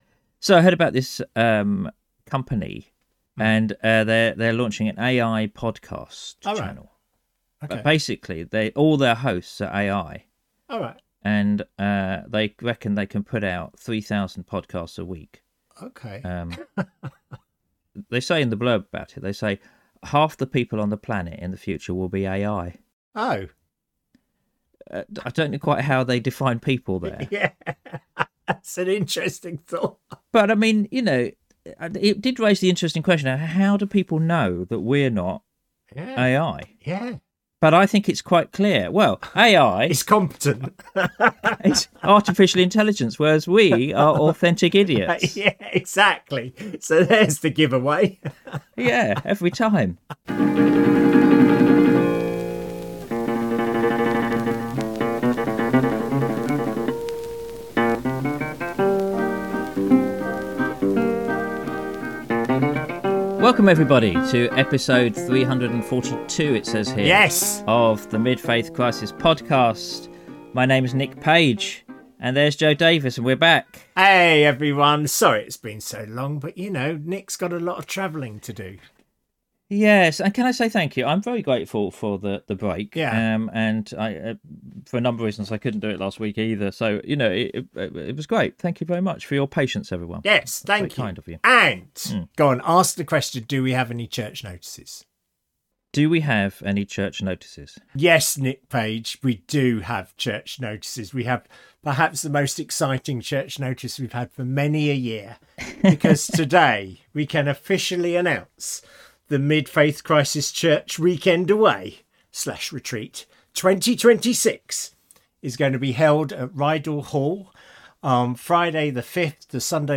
Episode 342: An interview with a very famous author